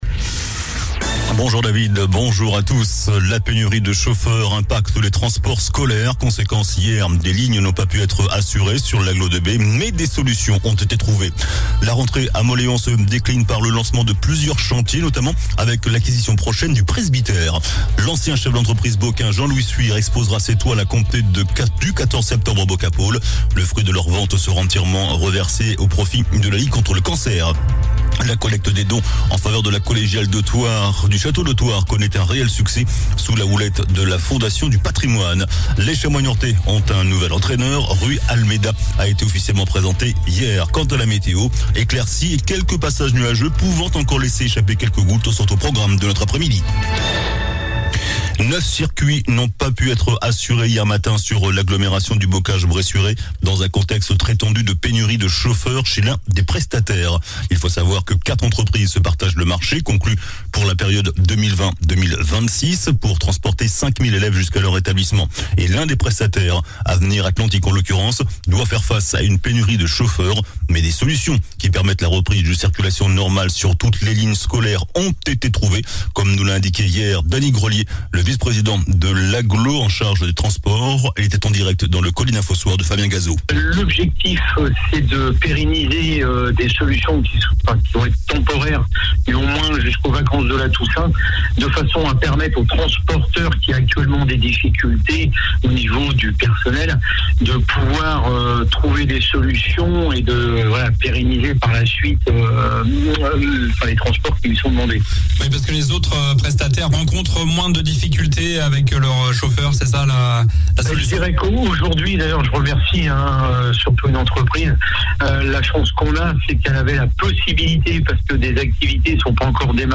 JOURNAL DU MARDI 06 SEPTEMBRE ( MIDI )